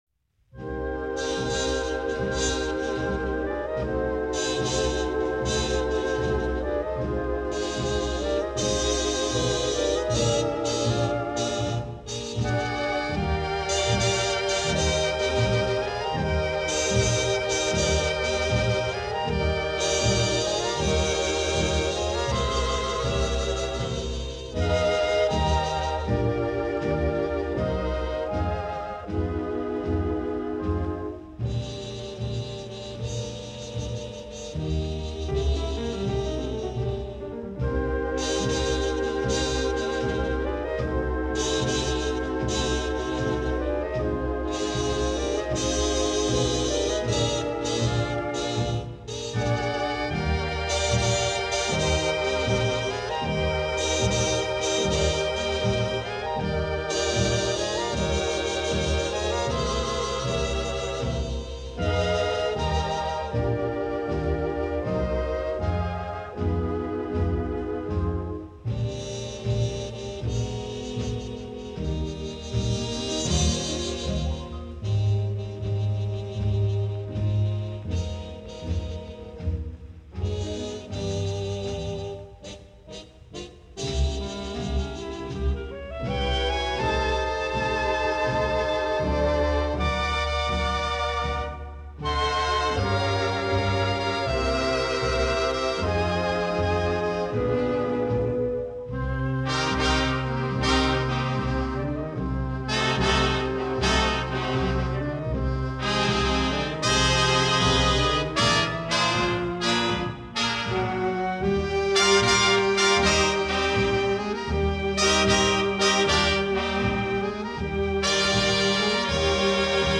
最富傳奇性的爵士搖擺大樂團，經現代科技數位處理，重現如上海時期百樂門舞廳般的四十年代華麗歌聲舞影。